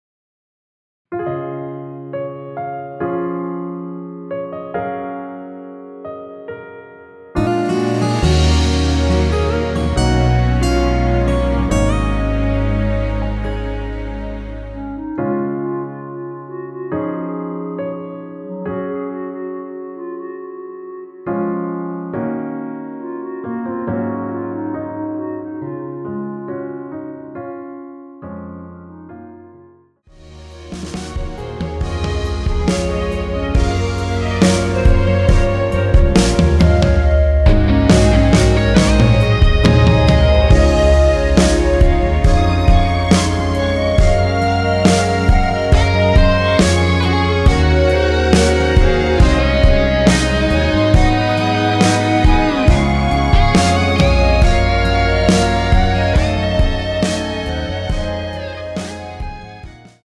원키 멜로디 포함된 MR입니다.(미리듣기 확인)
Db
노래방에서 노래를 부르실때 노래 부분에 가이드 멜로디가 따라 나와서
앞부분30초, 뒷부분30초씩 편집해서 올려 드리고 있습니다.